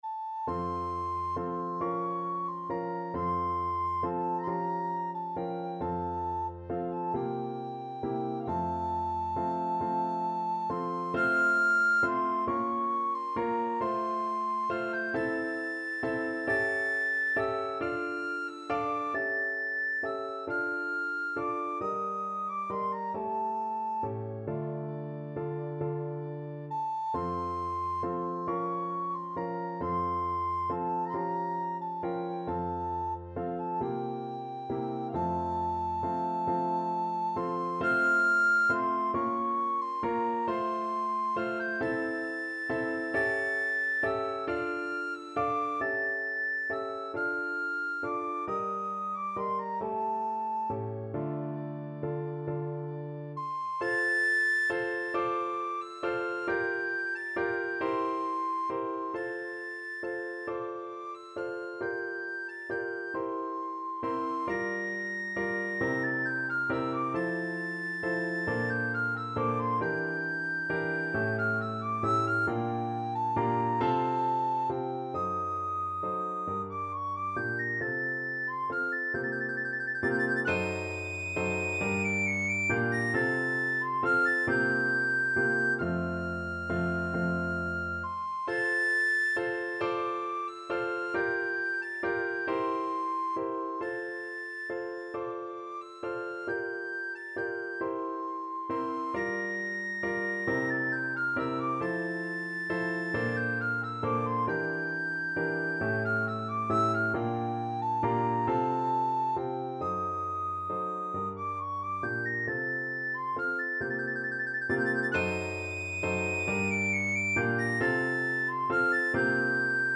Free Sheet music for Soprano (Descant) Recorder
Recorder
Andantino .=c.45 (View more music marked Andantino)
F major (Sounding Pitch) (View more F major Music for Recorder )
6/8 (View more 6/8 Music)
Classical (View more Classical Recorder Music)